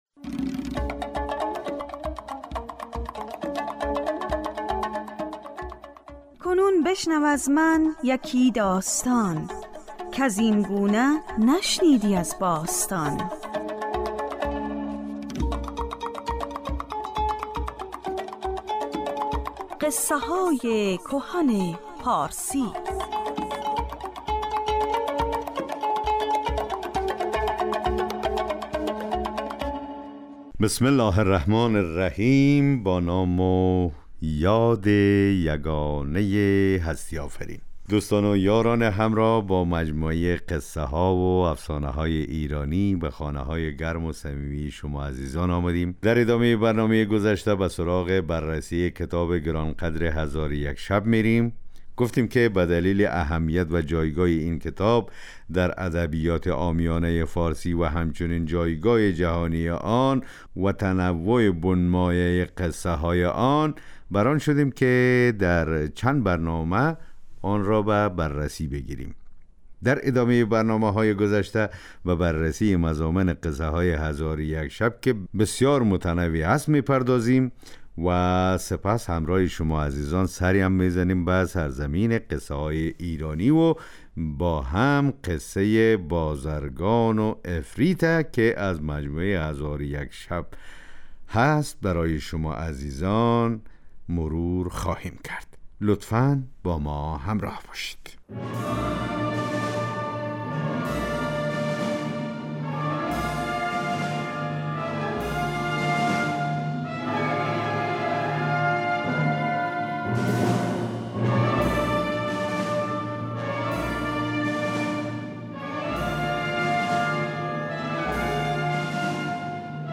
در بخش اول این برنامه به ادبیات پارسی پرداخته می شود و در بخش دوم یکی از داستان های هزار و یک شب روایت می شود.